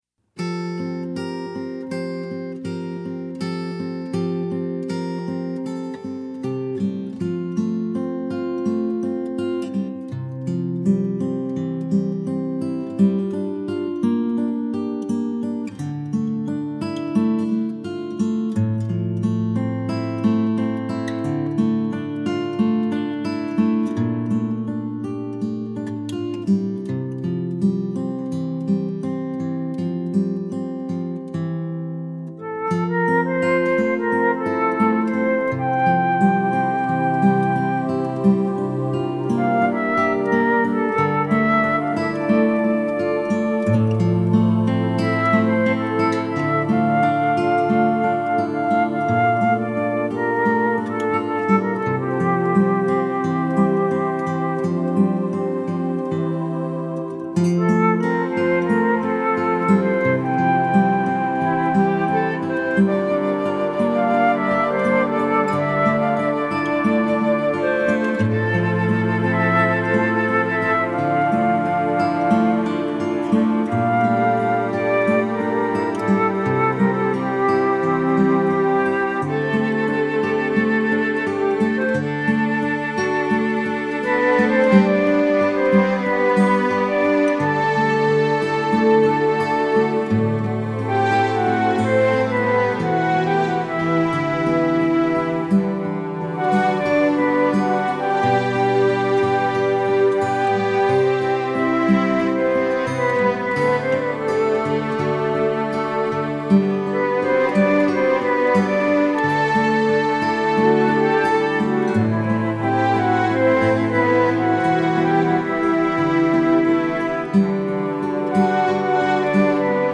Let these instrumentals take you on a musical journey of healing with their touching melodies.
Later on, I gravitated more to acoustic guitar, piano and piano, and solo piano.